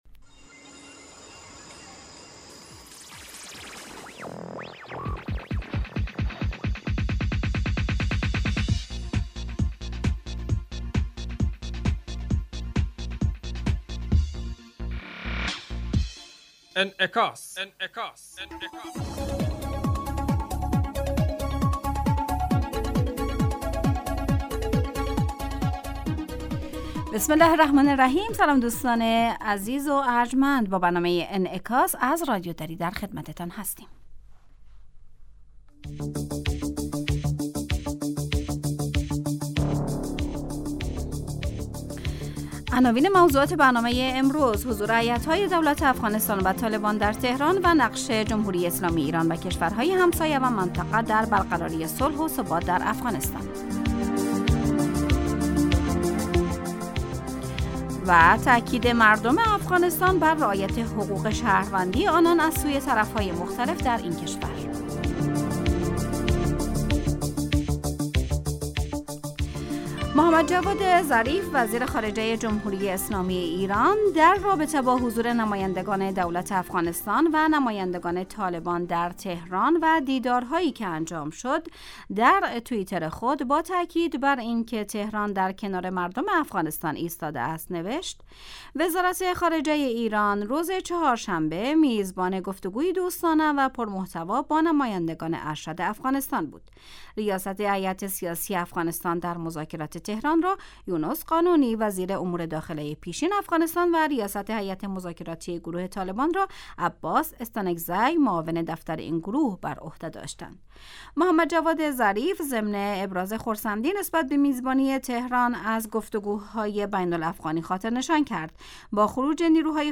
تاکید مردم افغانستان بر رعایت حقوق شهروندی آنان از سوی طرف های مختلف در این کشور. برنامه انعکاس به مدت 30 دقیقه هر روز در ساعت 12:10 ظهر (به وقت افغانستان) بصورت زنده پخش می شود. این برنامه به انعکاس رویدادهای سیاسی، فرهنگی، اقتصادی و اجتماعی مربوط به افغانستان و تحلیل این رویدادها می پردازد.